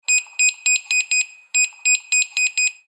Digital Code Entry Beeps – Door Unlock Sound Effect
This door unlock sound effect delivers crisp electronic beeps and digital code tones. It plays a smooth unlocking sound perfect for doors, safes, home safes, coding, robots, electronics, and security systems.
Digital-code-entry-beeps-door-unlock-sound-effect.mp3